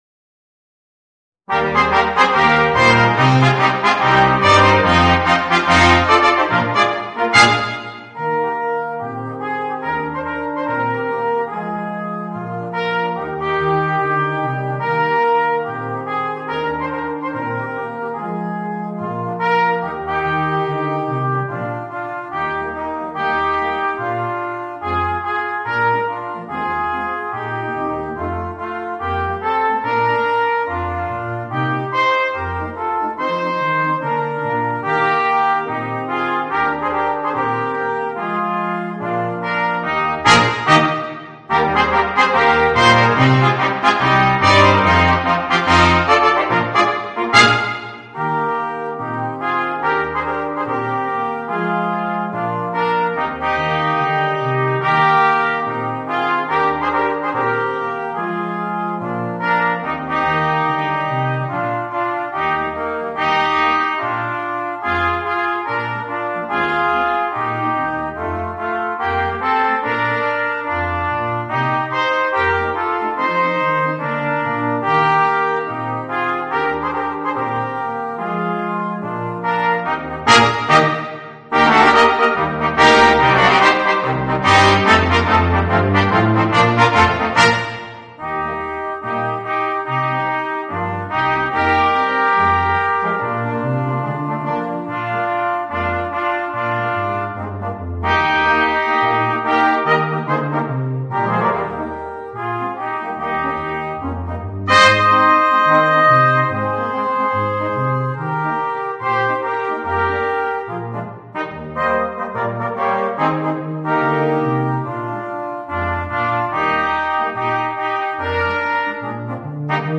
Voicing: 2 Trumpets, 2 Trombones and Tuba